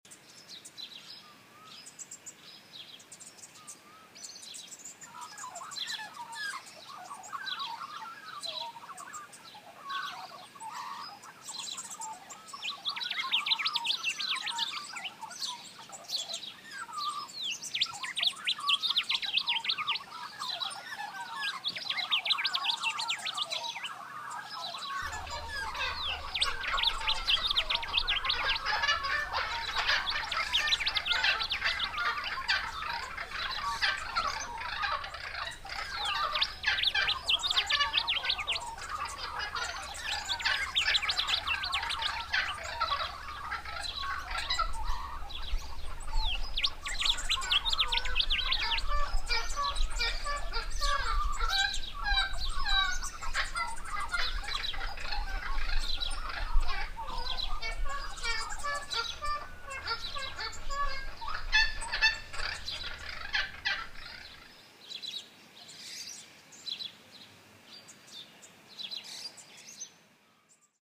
INDICATUS SOUNDTRACK (MIXED FROM MOTION SENSORED TRACKS), 2015 (Cementa_15)
indicatus_birdsnofire_soundcloudmix.mp3